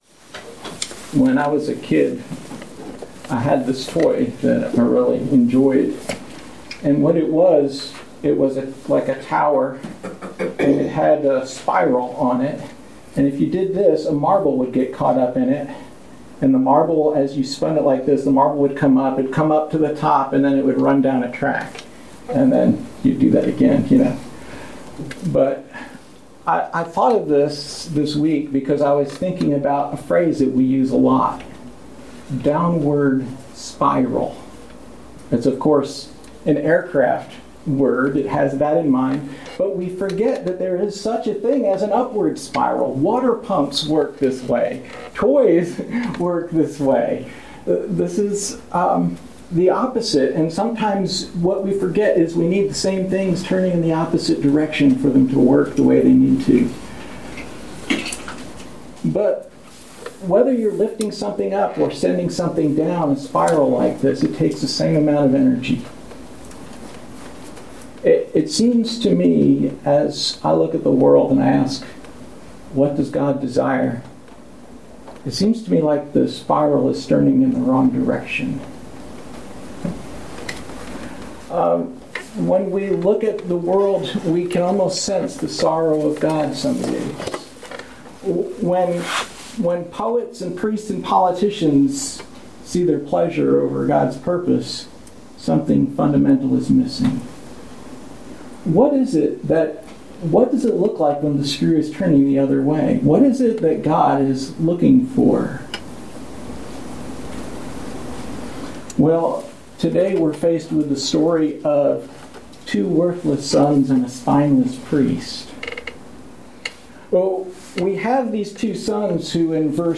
Passage: 1 Samuel 3:27-34 Service Type: Sunday Morning